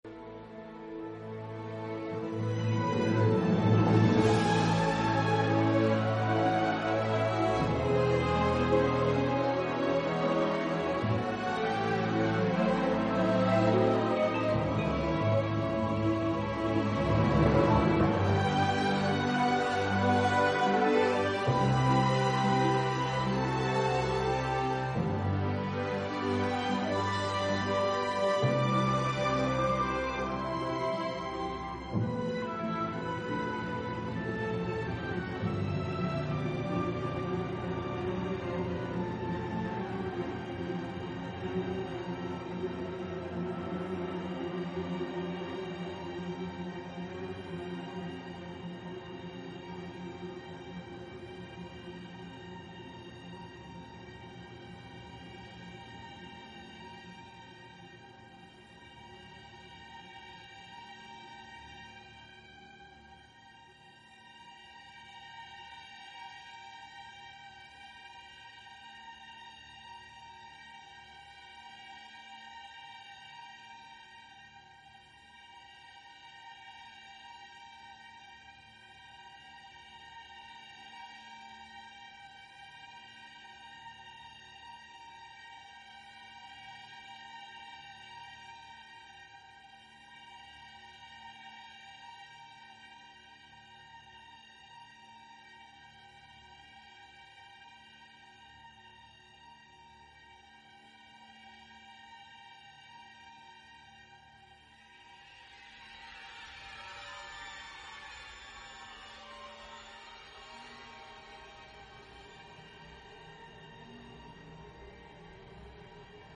A monologue from Pearl sound effects free download